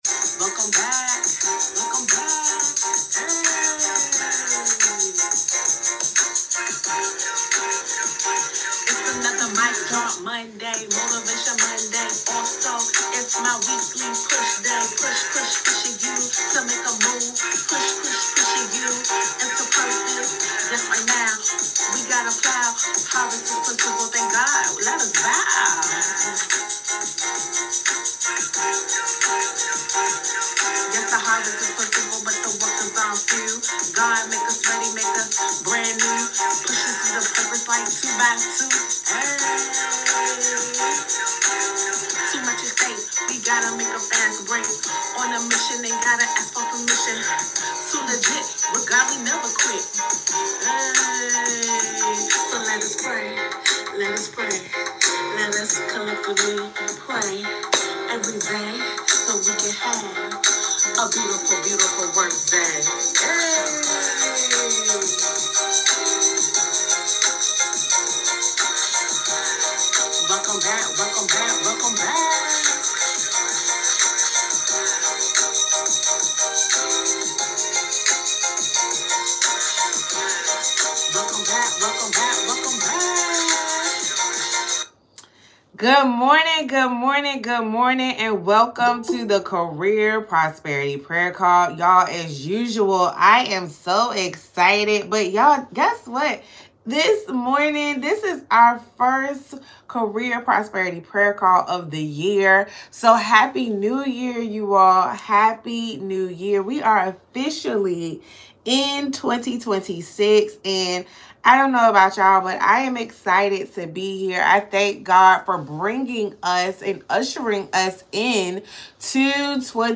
leading a prayer to "evict fear" and for God to increase capacity and provide divine favor